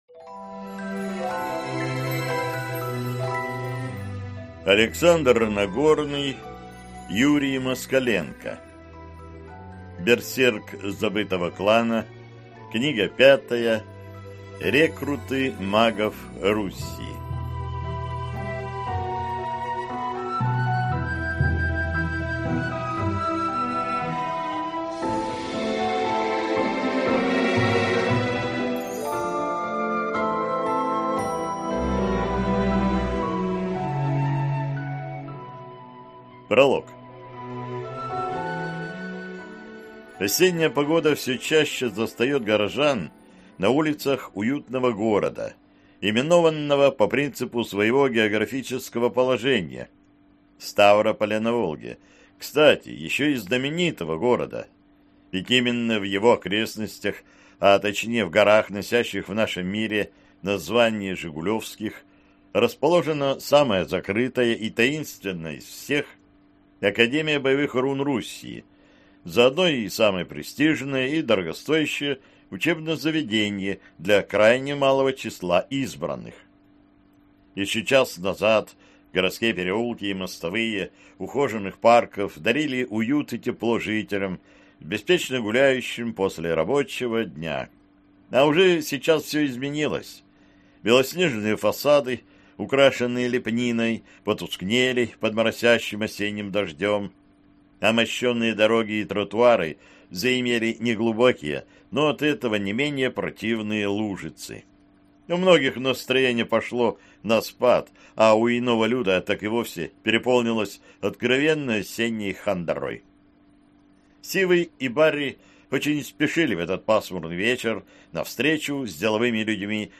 Аудиокнига Берсерк забытого клана.